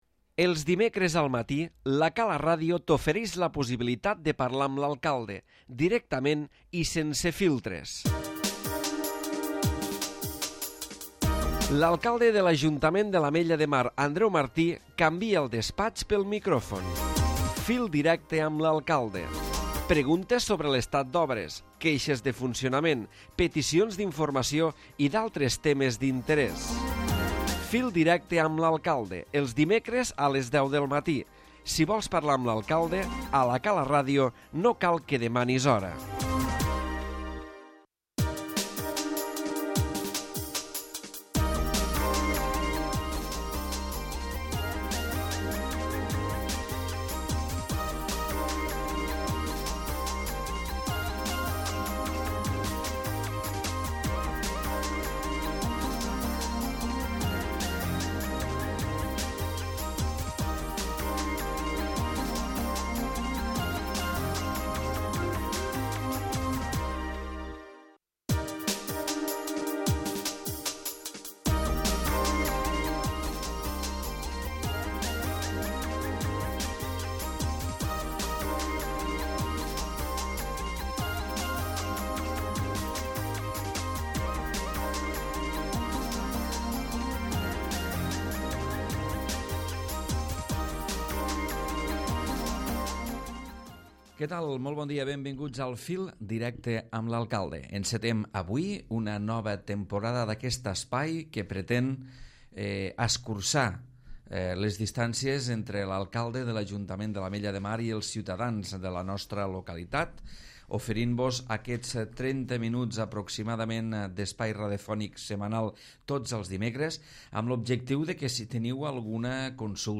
Andreu Martí, alcalde de l'Ajuntament de l'Ametlla de Mar ha participat al primer programa de la temporada del Fil Directe, espai de trucades dels ciutadans.